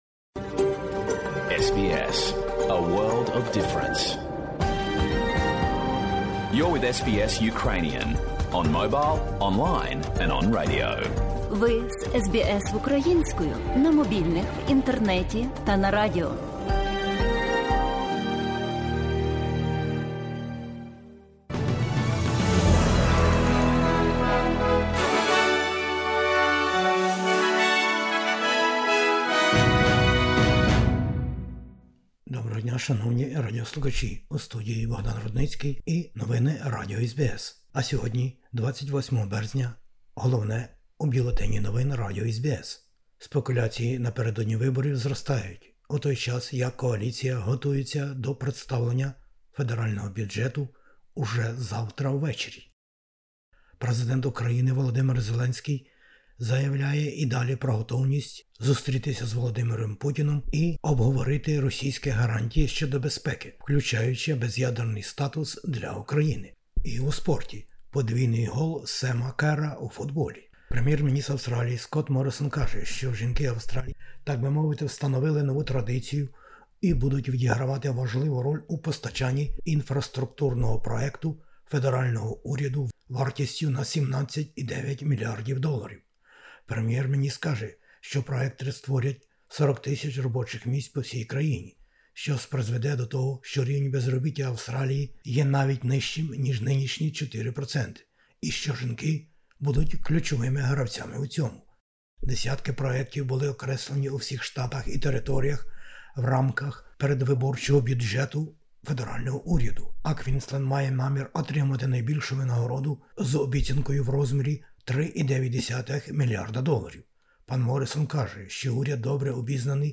Бюлетень новин SBS українською мовою.